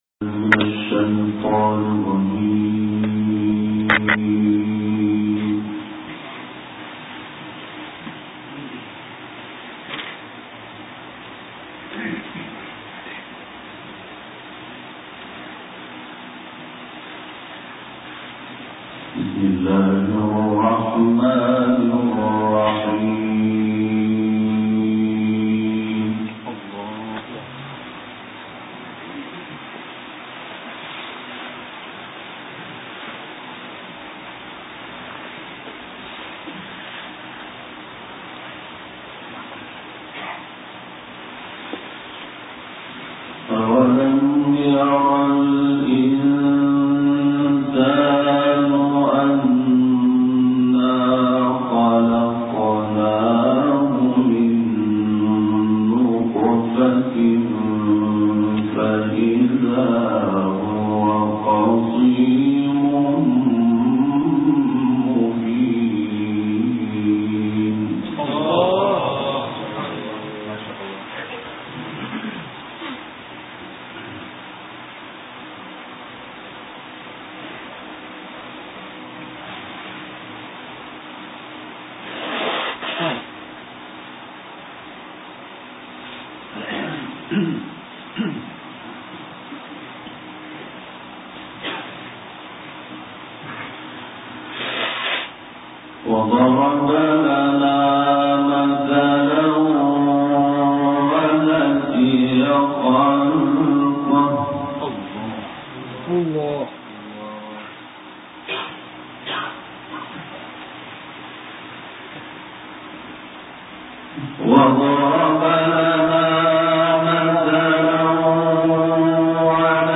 تلاوت سوره یس «محمود شحات»
گروه شبکه اجتماعی: تلاوت آیاتی از کلام الله مجید با صوت محمود شحات انور را می‌شنوید.